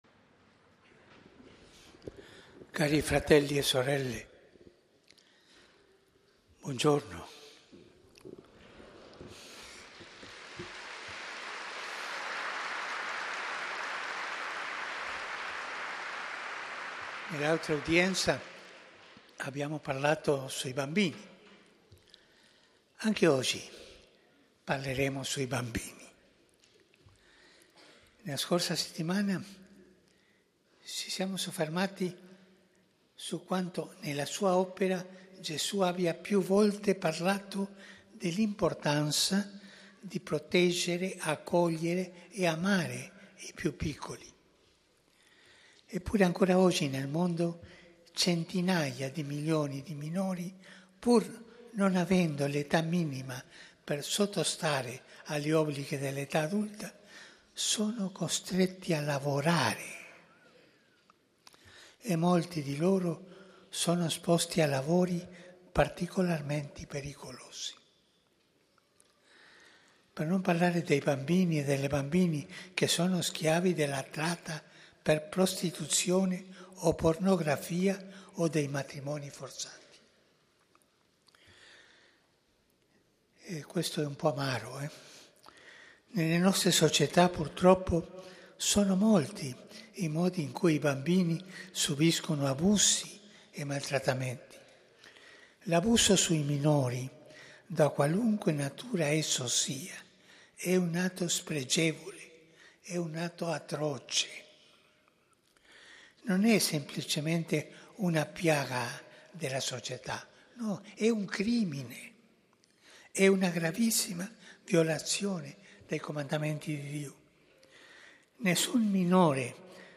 UDIENZA GENERALE di PAPA FRANCESCO
Aula Paolo VIMercoledì, 15 gennaio 2025